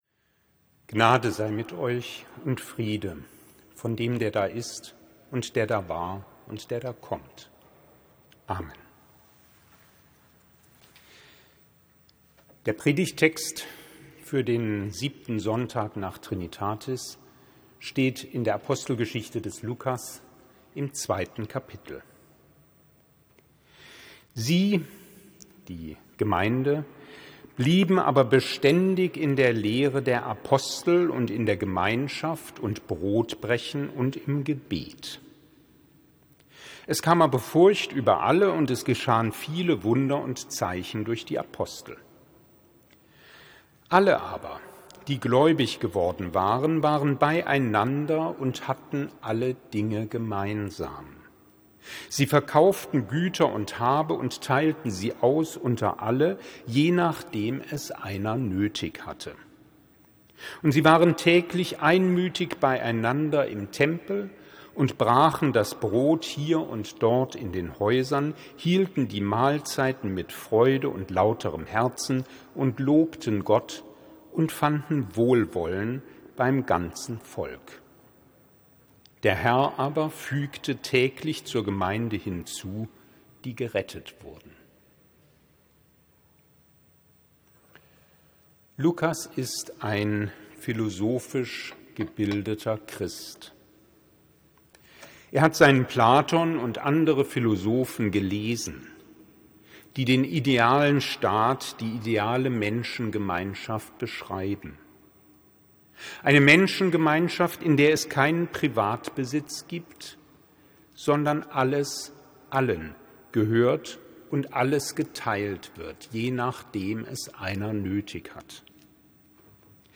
Sermons 2023